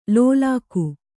♪ lōlāku